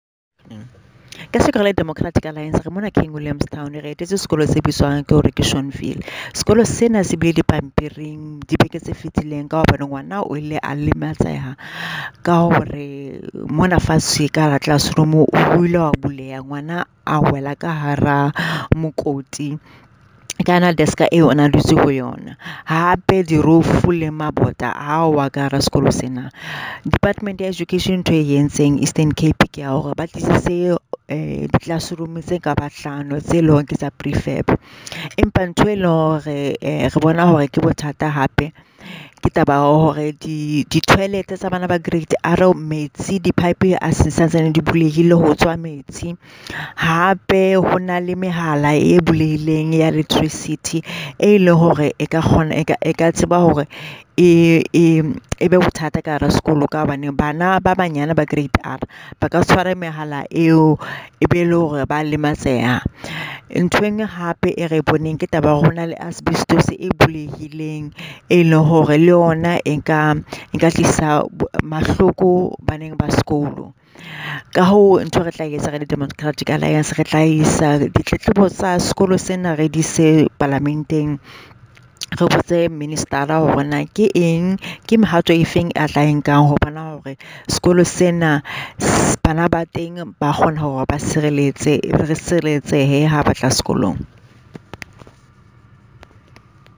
The statement below follows an oversight visit to Schornville Primary School by DA Shadow Deputy Minister of Basic Education, Nomsa Marchesi MP, and DA Shadow MEC for Education in the Eastern Cape, Edmund van Vuuren MPL. Please find attached soundbites by Nomsa Marchesi MP in